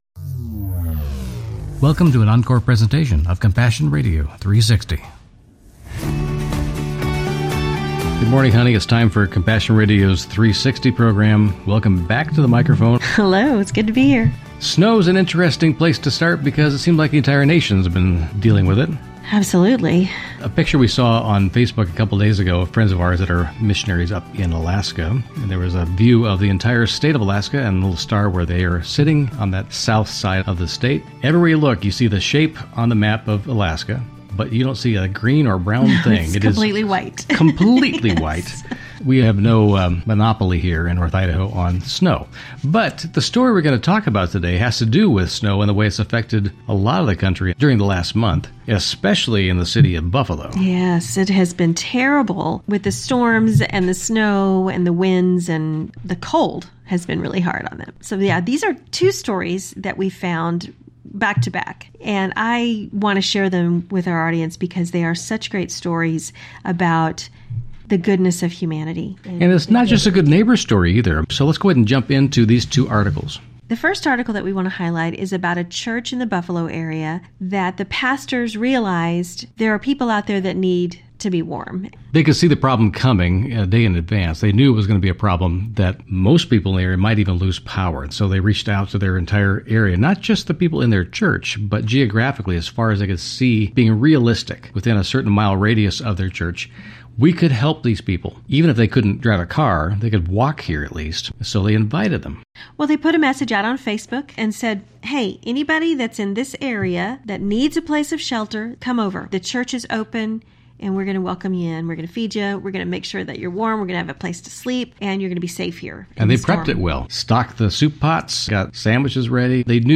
Genre: Christian News Teaching and Talk.